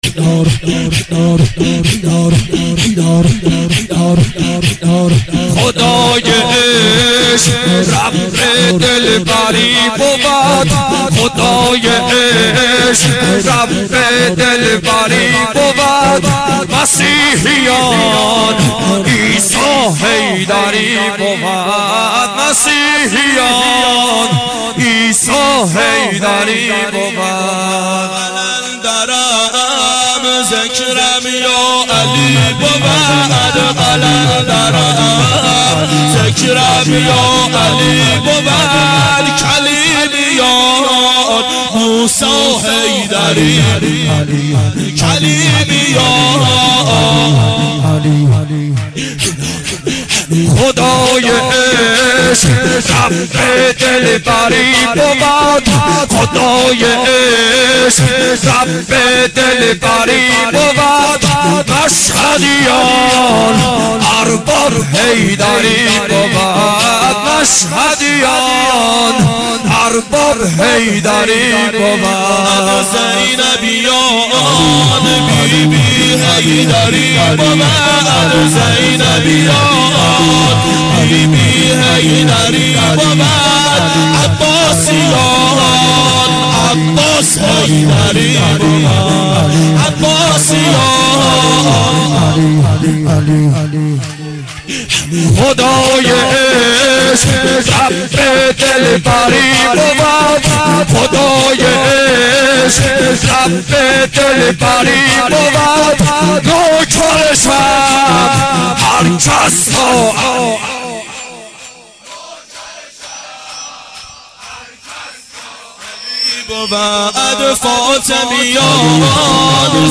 اربعین 1389 هیئت متوسلین به امیرالمومنین علی علیه السلام